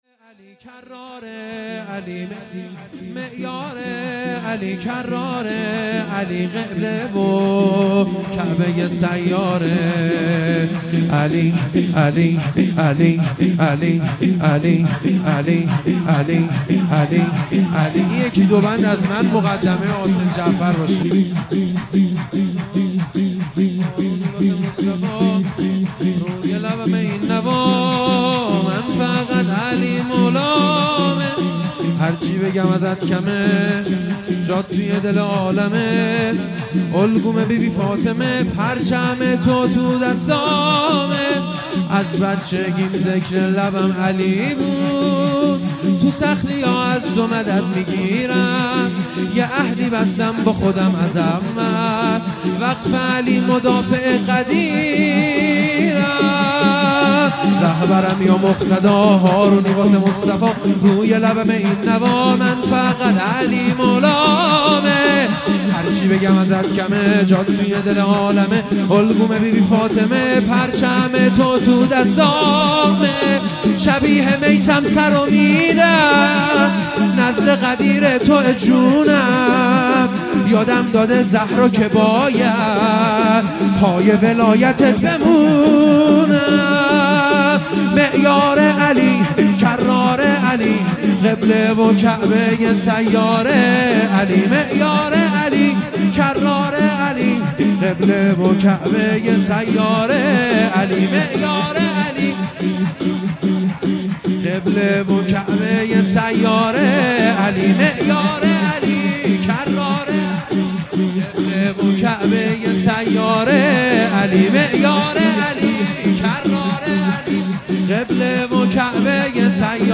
عید غدیر خم